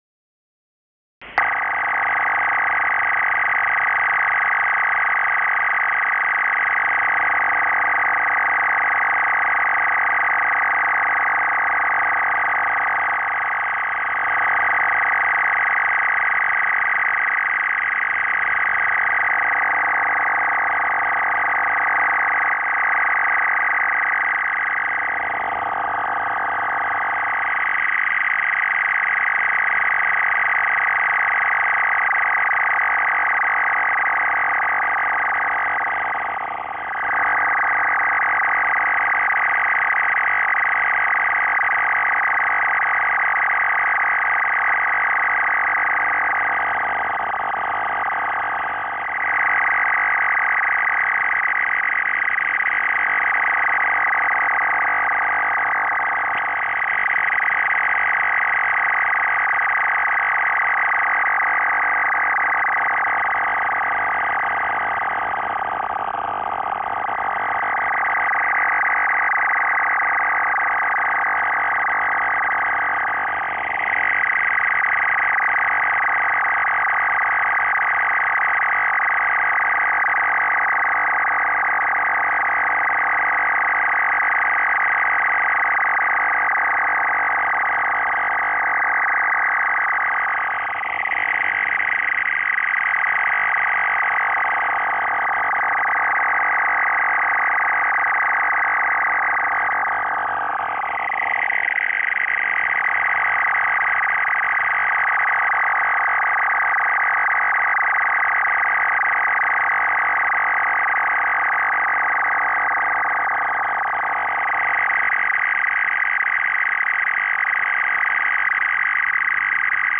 4-FSK 200 Bd
MFSK-4 4-TONE 200 Bd MFSK SYSTEM
4-FSK 200 Bd with call-up and traffic sequence
4FSK_200Bd.WAV